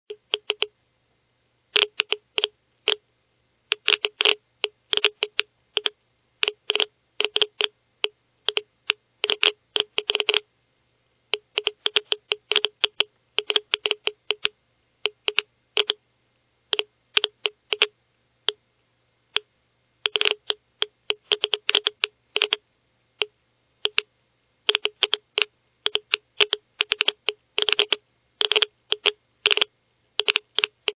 geiger_counter.mp3